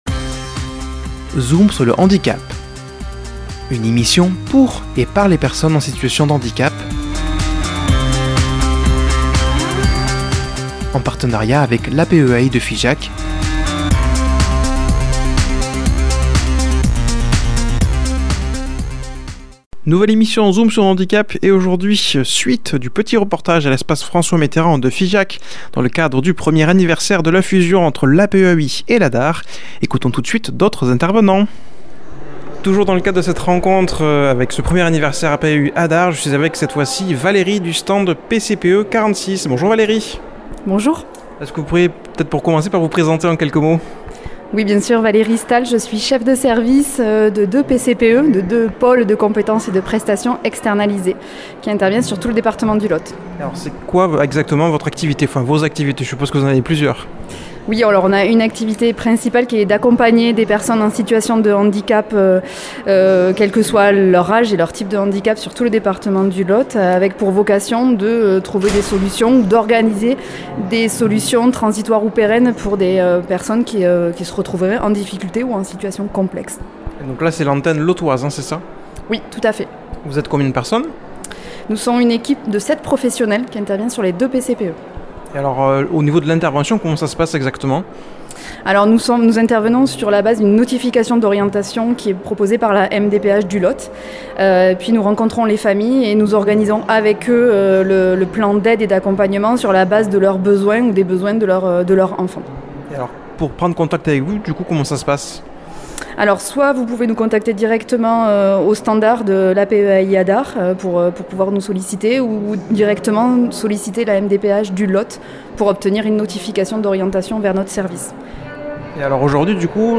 Nouvelle émission Zoom sur le Handicap et aujourd’hui suite et fin du reportage dans le cadre d’une après midi consacré au 1er anniversaire de la fusion entre l’APEAI et l’ADAR.